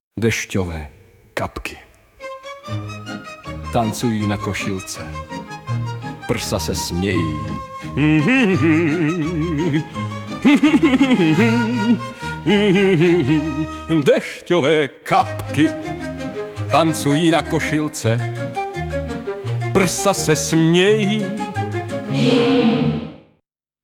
2012 & Hudba a Zpěv: AI Fotka od OpenClipart-Vectors z Pixabay